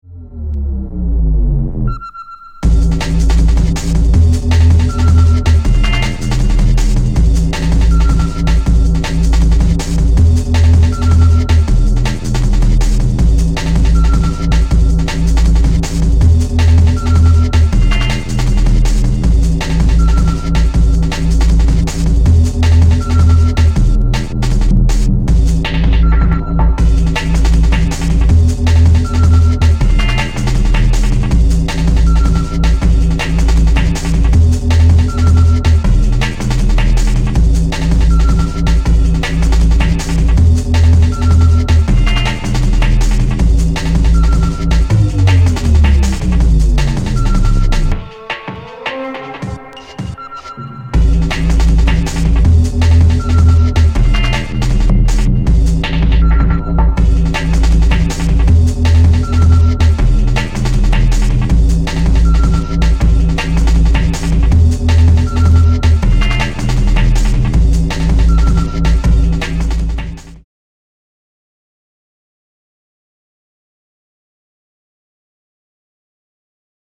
Jungle/Drum n Bass
Drum & Bass